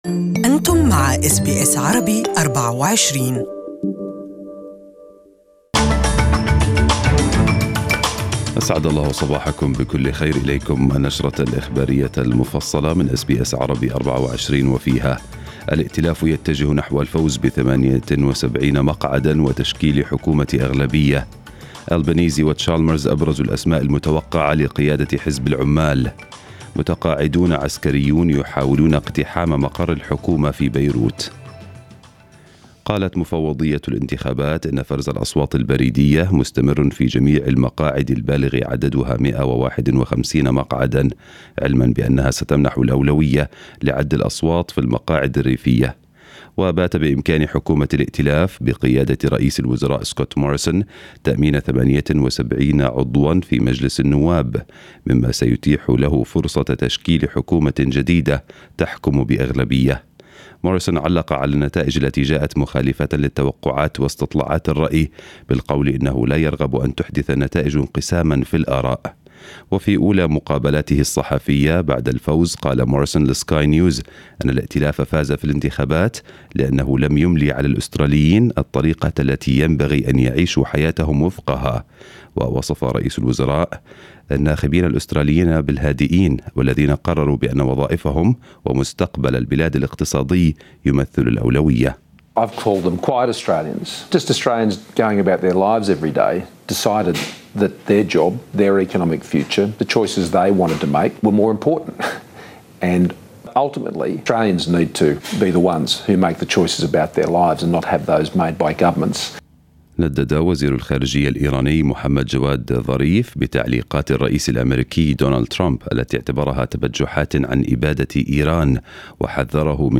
News bulletin for the day in Arabic